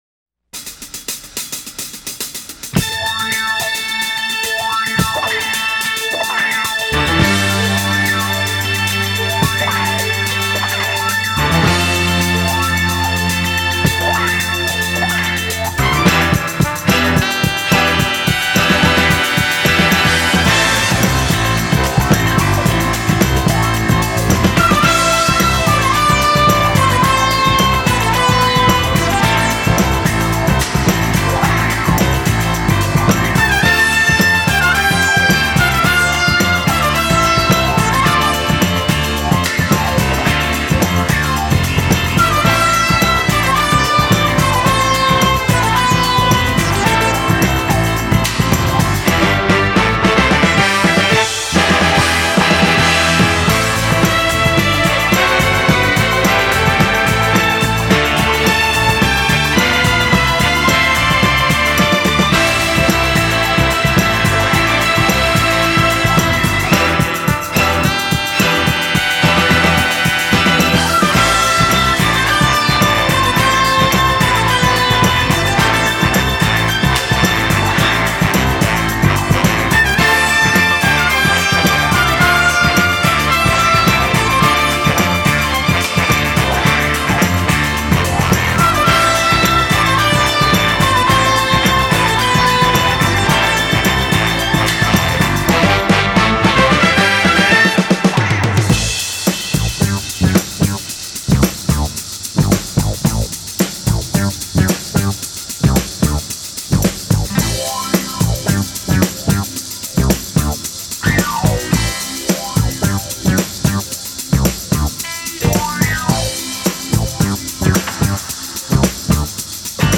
(инструментал)